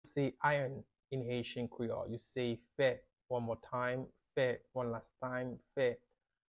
“Iron” in Haitian Creole – “Fè” pronunciation by a native Haitian teacher
“Fè” Pronunciation in Haitian Creole by a native Haitian can be heard in the audio here or in the video below:
How-to-say-Iron-in-Haitian-Creole-–-Fe-pronunciation-by-a-native-Haitian-teacher.mp3